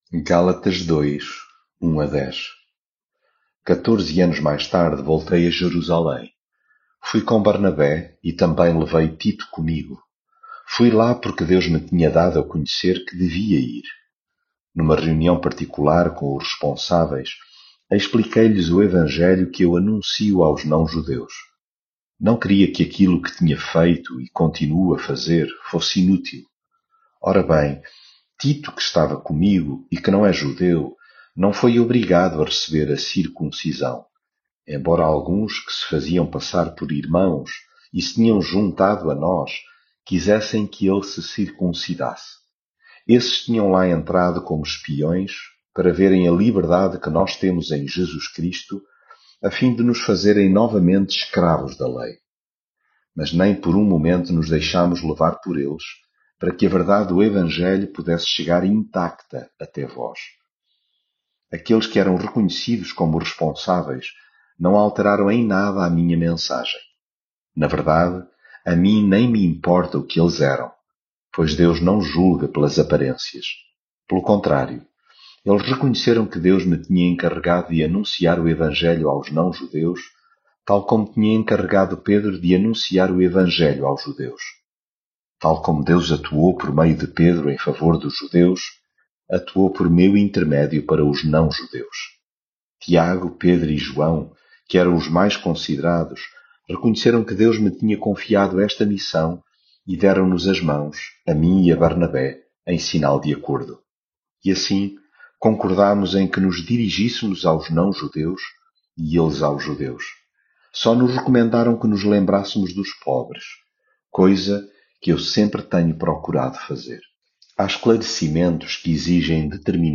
leitura bíblica